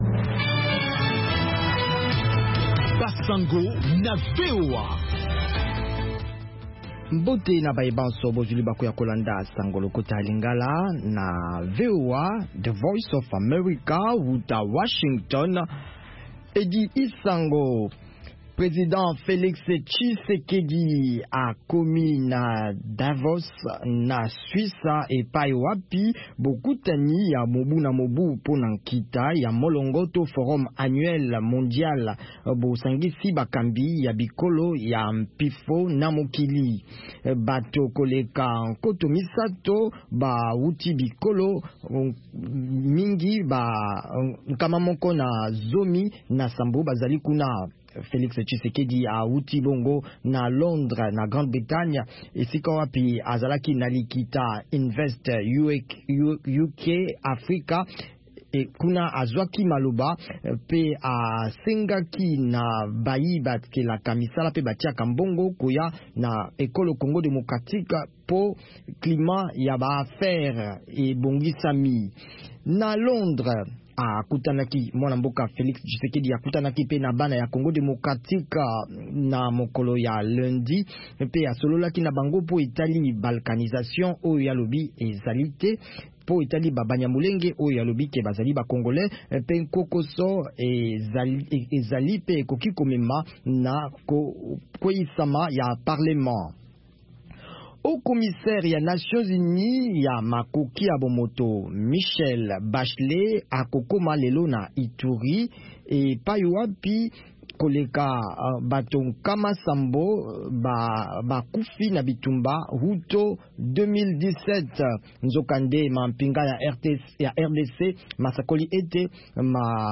Basango na VOA Lingala